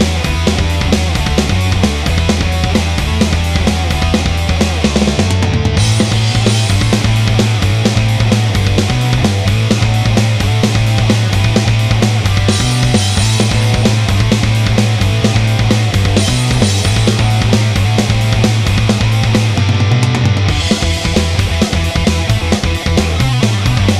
no Backing Vocals Rock 4:31 Buy £1.50